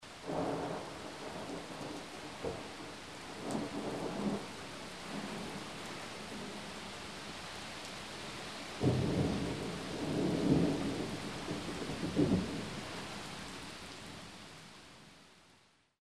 rain_thunder04.mp3